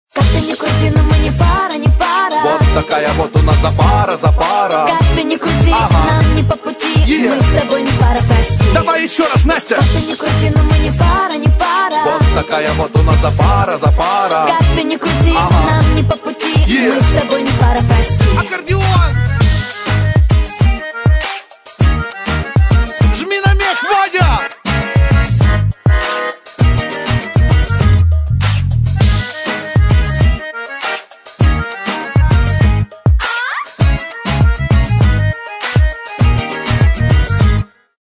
Рэп, Хип-Хоп, R'n'B [75]